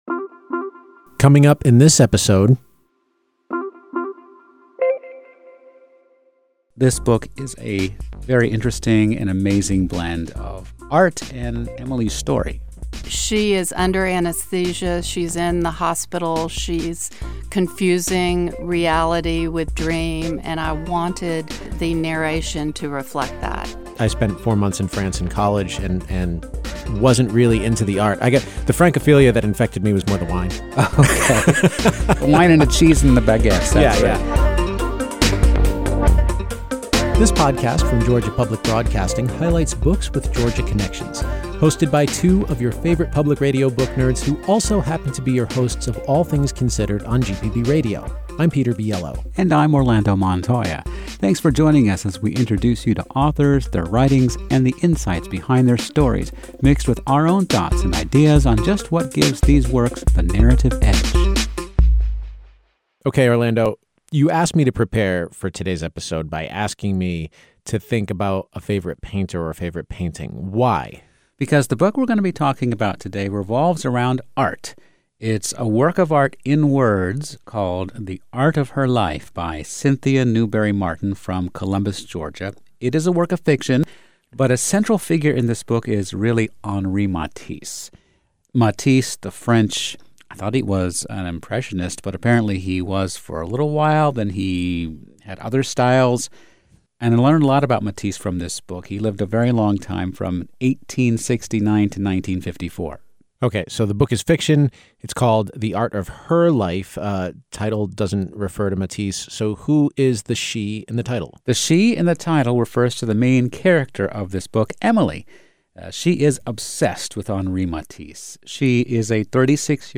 … continue reading 40 epizódok # Society # Books # Arts # Georgia Public Broadcasting # Lifestyle # Hobbies # Read # Review # NPR # GPB # Interviews # Authors